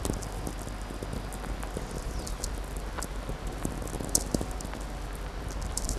Warsaw, Poland